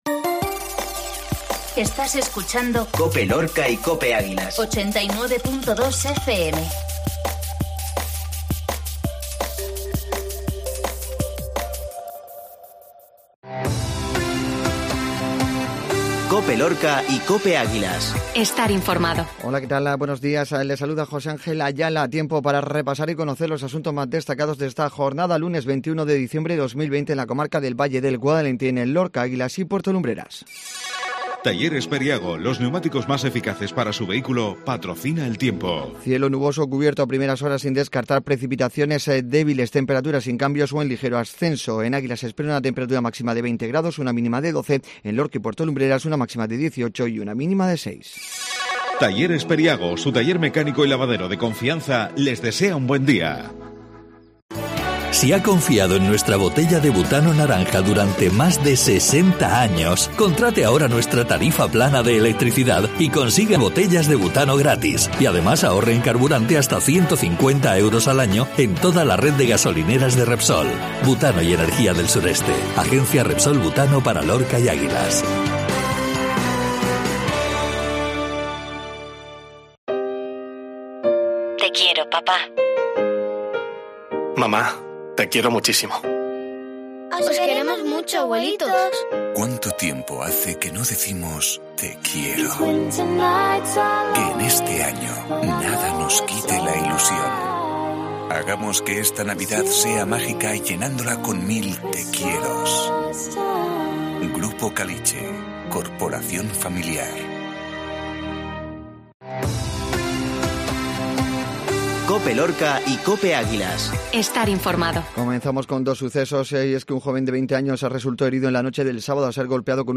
INIFORMATIVO MATINAL LUNES